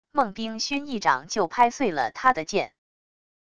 孟冰熏一掌就拍碎了他的剑wav音频生成系统WAV Audio Player